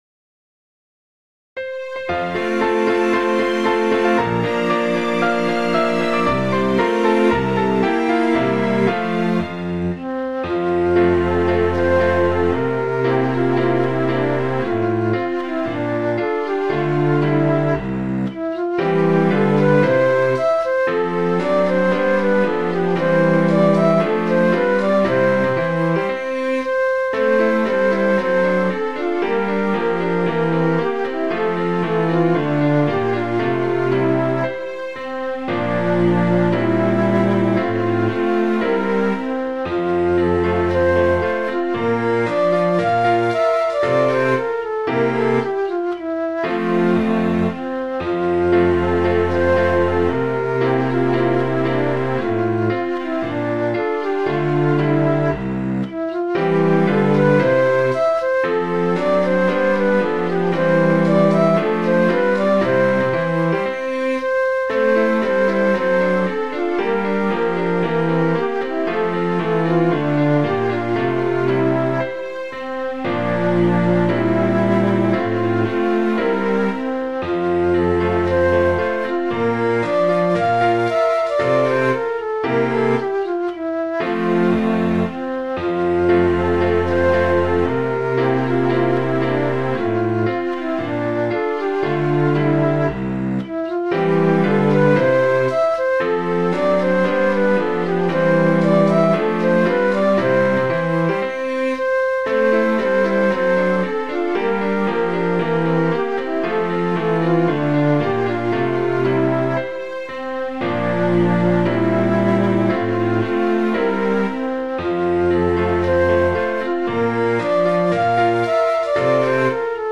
Midi File, Lyrics and Information to The Lass That Loves A Sailor